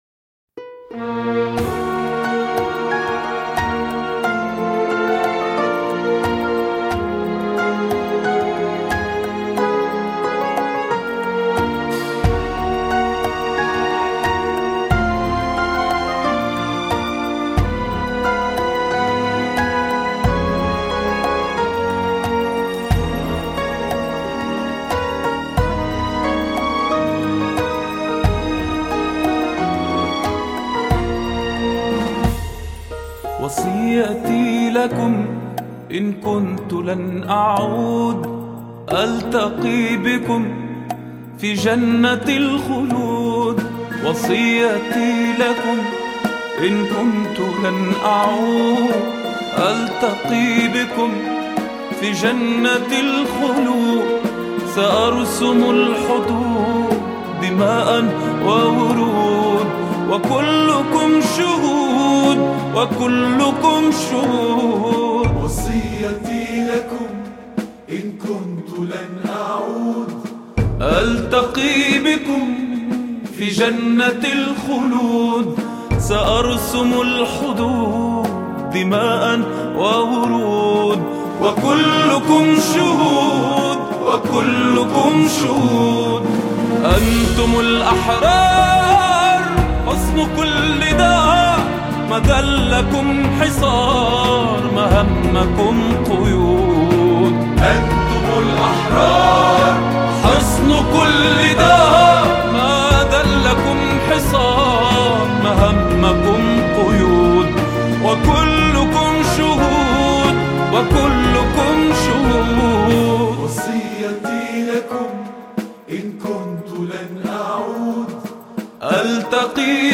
أناشيد فلسطينية